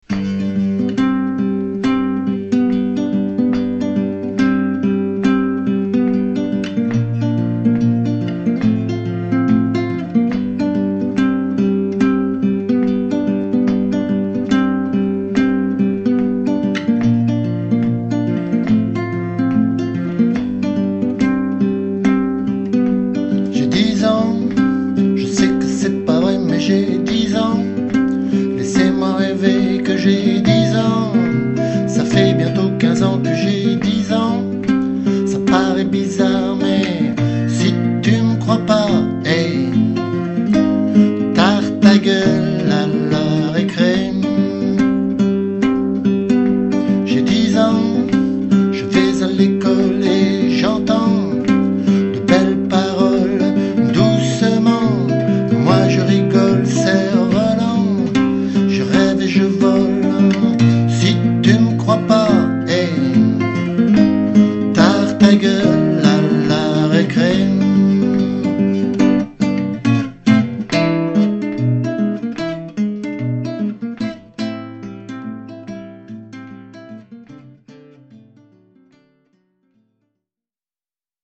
démo
capo 3eme case